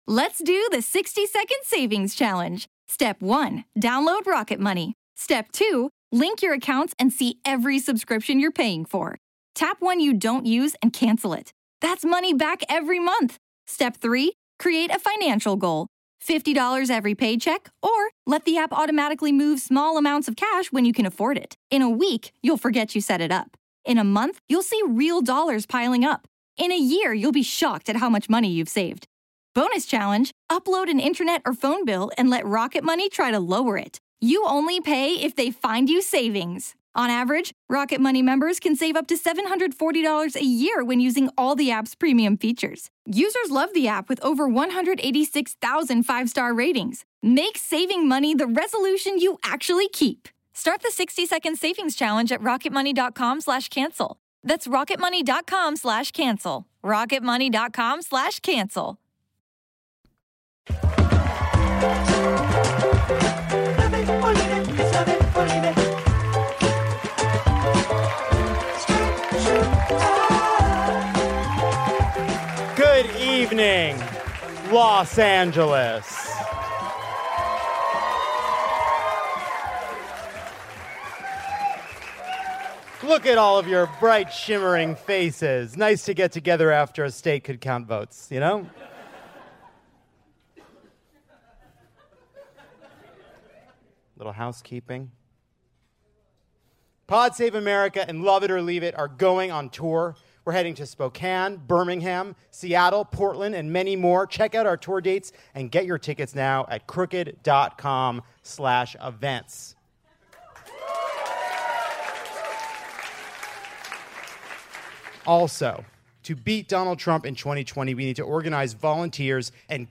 Up and coming journalist Ronan Farrow stops by to discuss revelations in his podcast AND relationship. Plus similarities between Nixon and Trump, life on a coronavirus cruise, and the power of Dwyane Wade and Gabrielle Union's example.